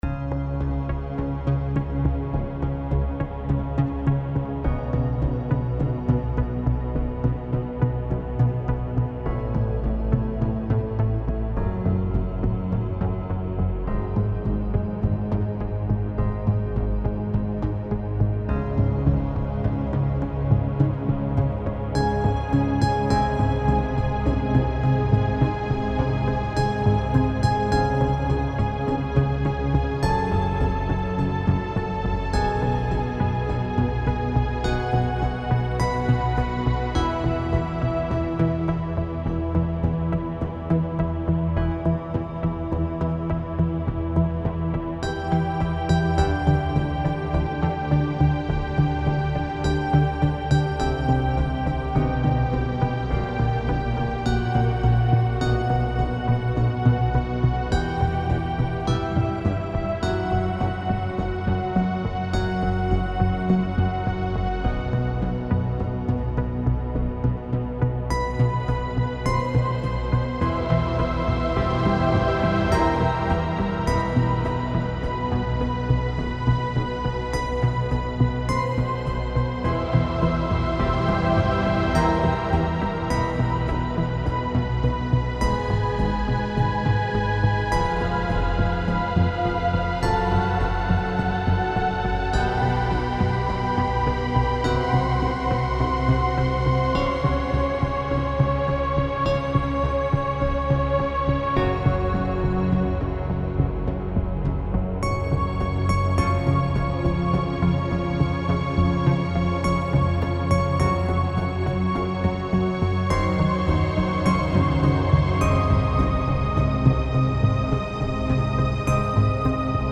Newage Ньюэйдж
Ambient